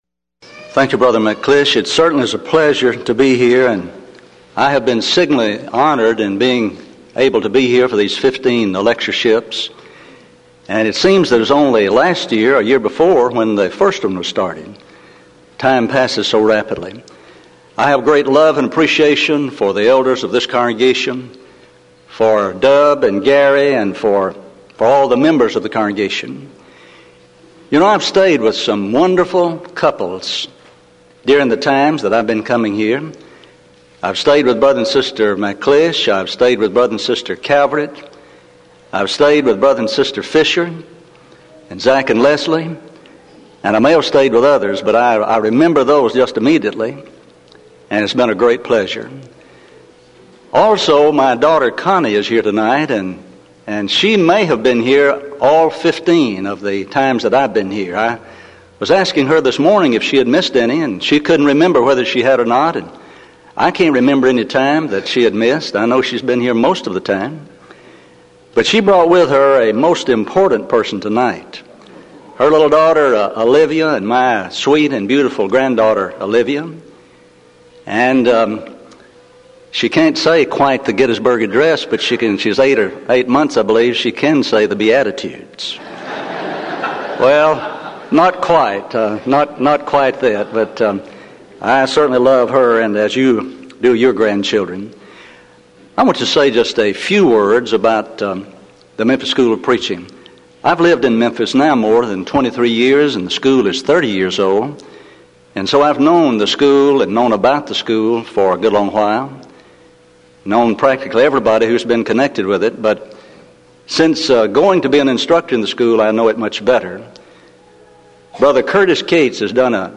Event: 1996 Denton Lectures